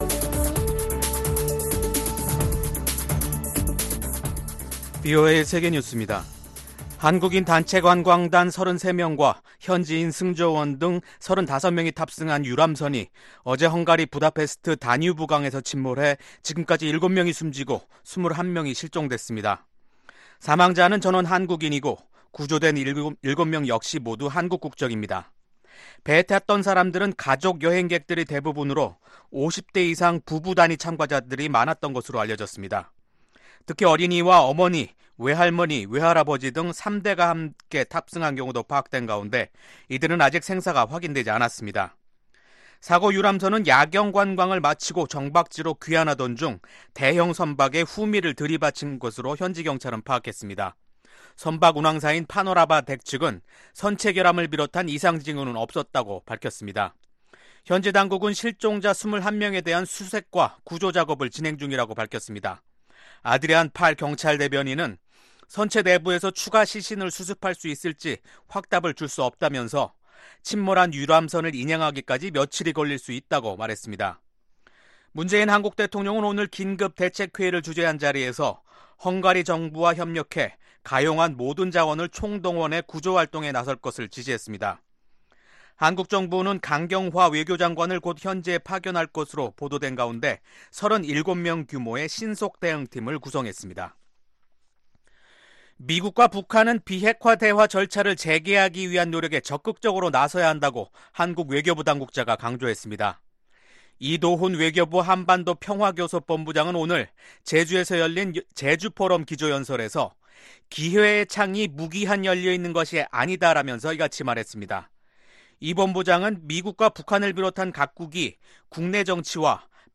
VOA 한국어 간판 뉴스 프로그램 '뉴스 투데이', 2019년 5월 30일 2부 방송입니다. 미국 국무부는 북한의 대량살상무기 프로그램 전체가 유엔 제재 위반이지만 현재로선 외교에 집중하고 있다고 거듭 밝혔습니다. 조셉 던포드 미국 합참의장은 미-한 연합훈련의 조정이 동맹의 전쟁 수행 능력을 약화시키지 않았다고 강조했습니다.